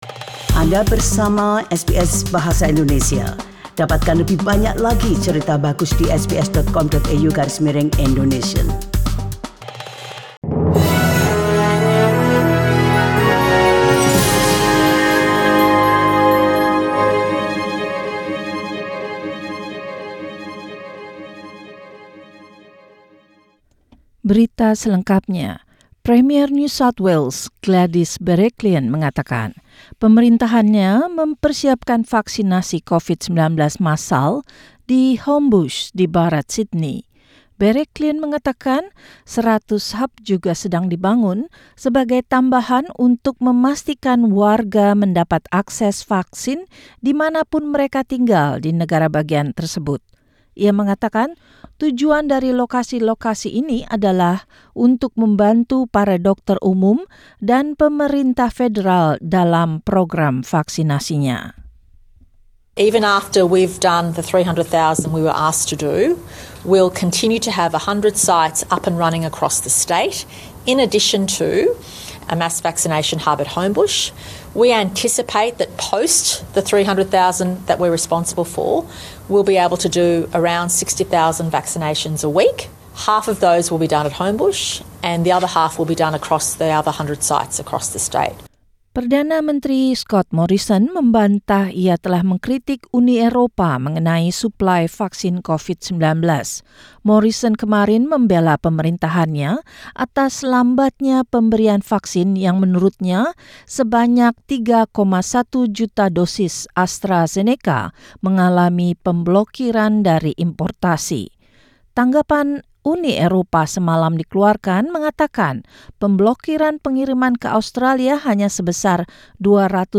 SBS Radio News in Indonesian, Wednesday, 7 April 2021